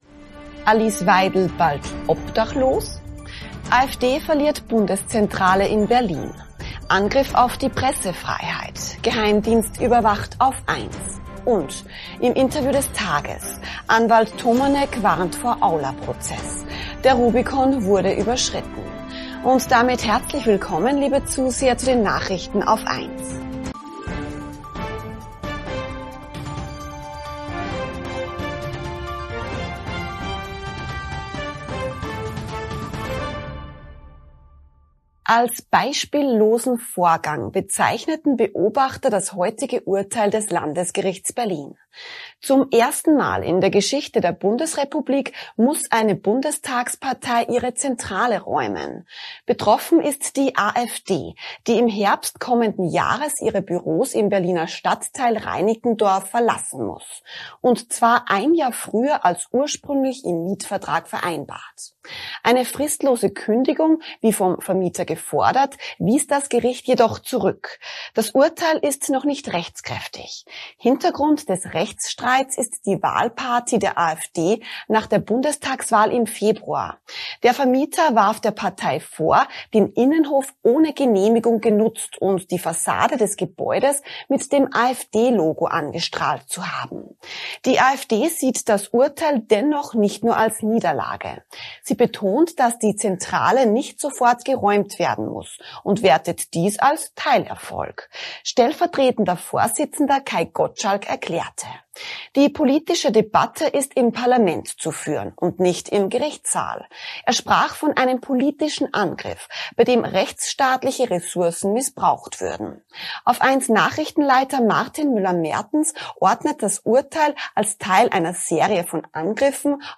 Im Interview des Tages